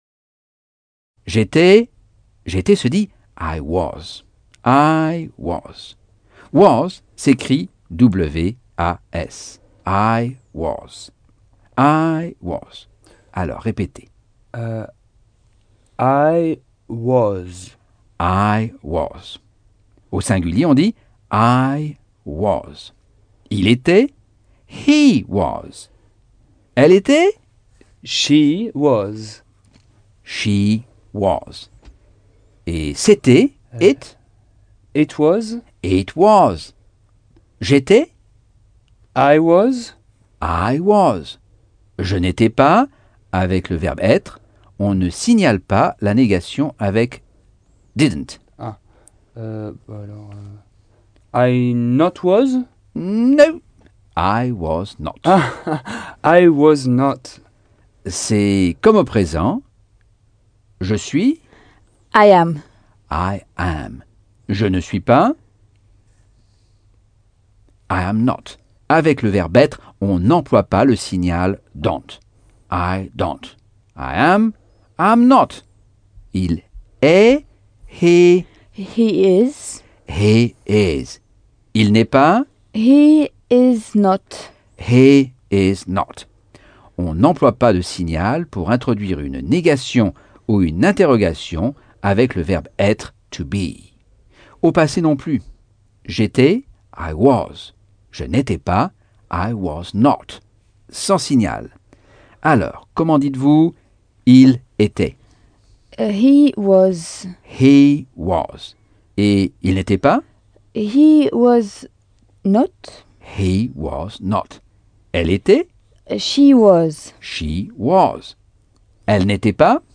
Leçon 1 - Cours audio Anglais par Michel Thomas - Chapitre 6